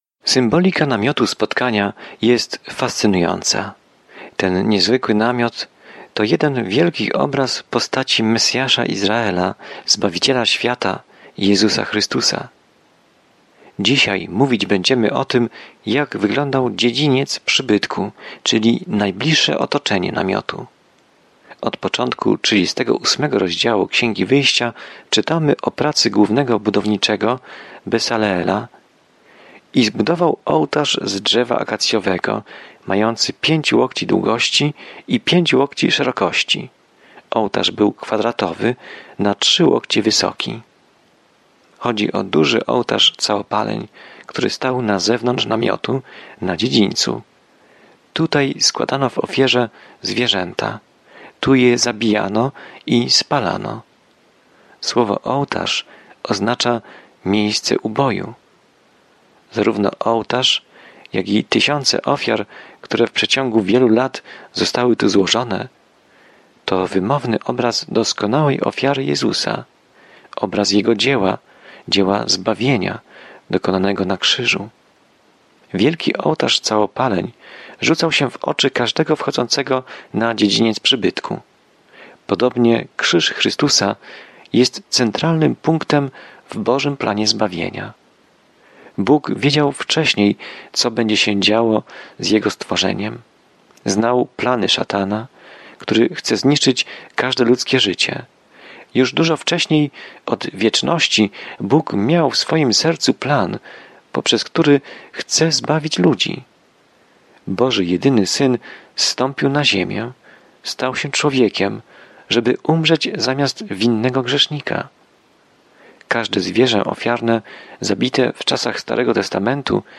Pismo Święte Wyjścia 38 Dzień 25 Rozpocznij ten plan Dzień 27 O tym planie Exodus śledzi ucieczkę Izraela z niewoli w Egipcie i opisuje wszystko, co wydarzyło się po drodze. Codzienna podróż przez Exodus, słuchanie studium audio i czytanie wybranych wersetów słowa Bożego.